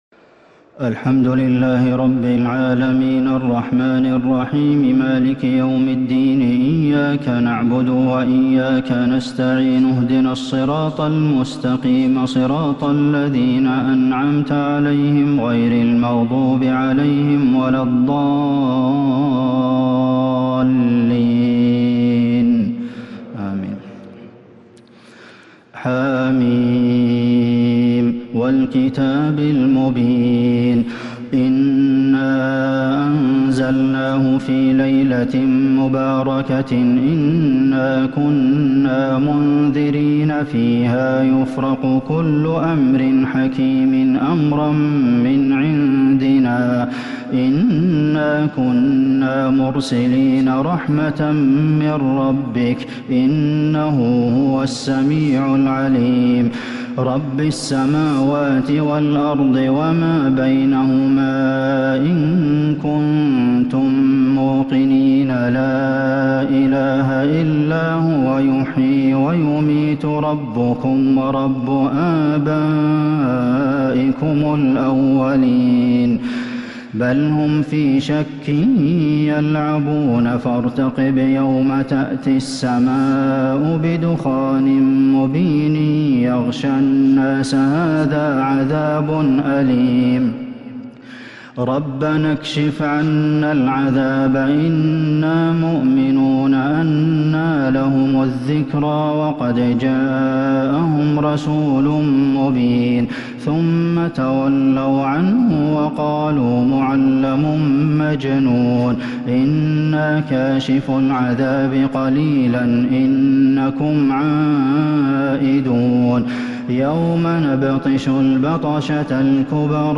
صلاة التراويح l ليلة 27 رمضان 1442 l سورة الدخان والجاثية والأحقاف | taraweeh prayer The 27rd night of Ramadan 1442H | from surah Ad-Dukhaan and Al-Jaathiya and Al-Ahqaf > تراويح الحرم النبوي عام 1442 🕌 > التراويح - تلاوات الحرمين